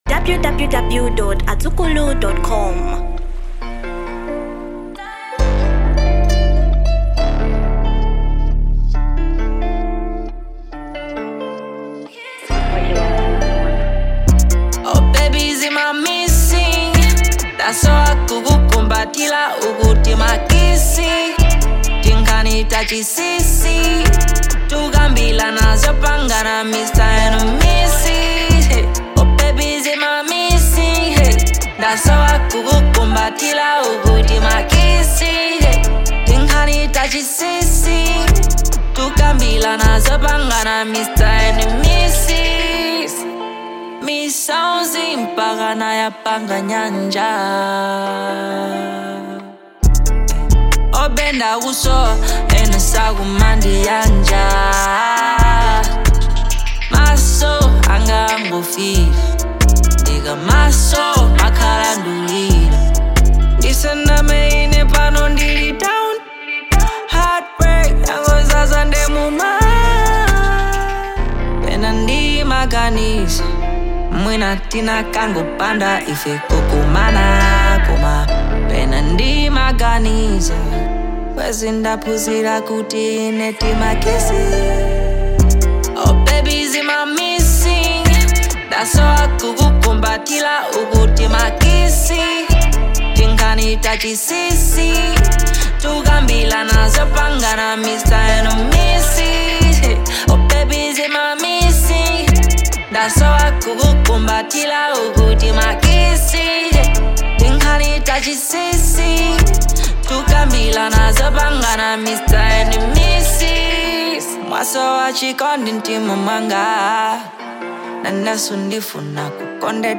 Genre Hip-hop